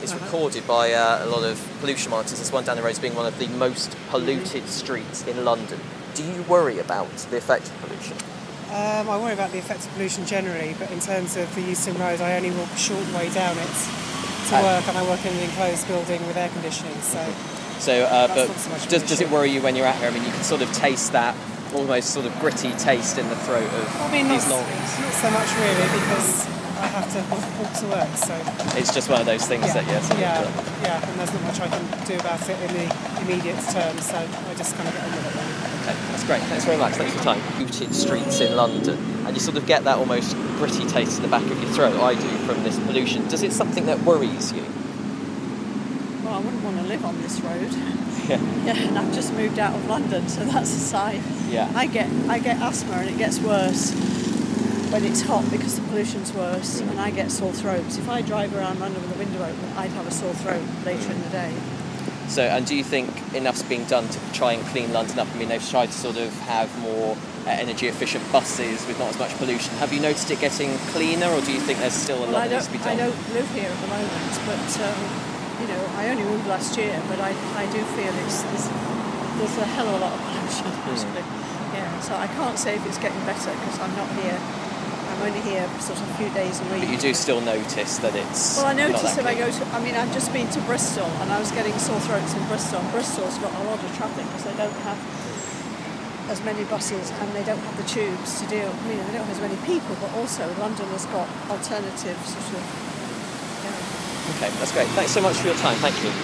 Euston voxes